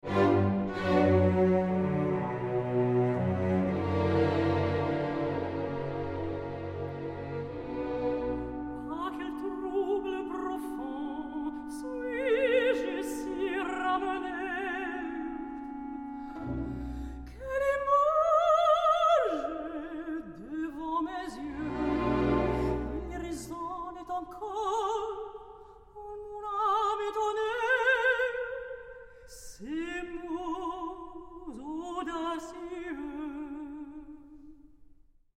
Rare French and Italian Opera Arias
Soprano
Released in stunning Super Audio CD surround sound.